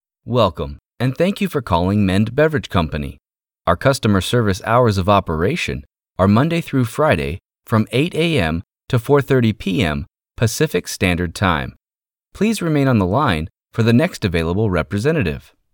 Mature Adult, Adult, Young Adult
Has Own Studio
southern us
standard us
authoritative
dramatic
friendly